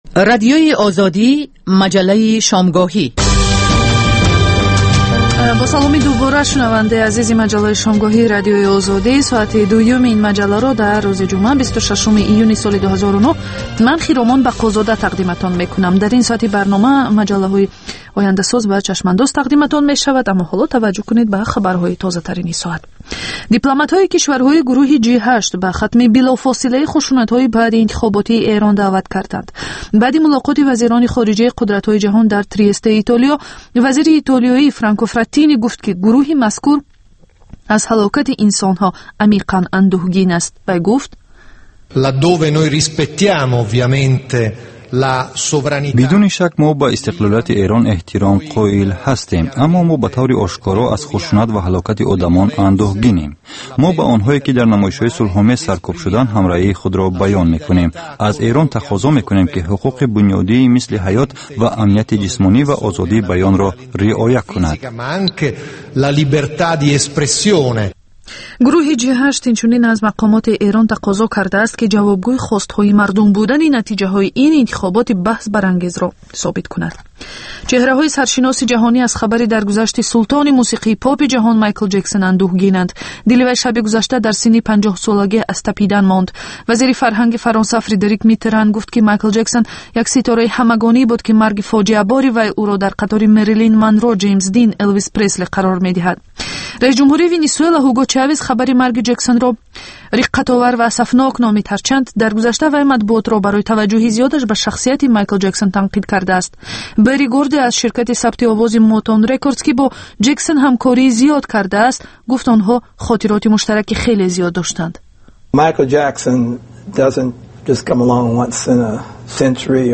"Ояндасоз" барномаи вижаи ҷавонон, ки муҳимтарин масоили сиёсӣ, иқтисодӣ, иҷтимоӣ ва фарҳангии Тоҷикистону ҷаҳонро аз дидгоҳи худи онҳо ва коршиносон таҳлил ва баррасӣ мекунад. Бар илова, дар ин гуфтор таронаҳои ҷаззоб ва мусоҳибаҳои ҳунармандон тақдим мешавад.